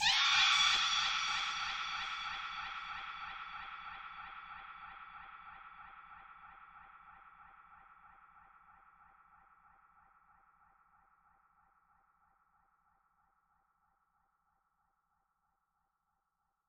不要回头看风琴
描述：我为这个令人毛骨悚然的万圣节类型的节拍做了准备，叫做"不要回头看quot。
Tag: 115 bpm Trap Loops Organ Loops 1.40 MB wav Key : C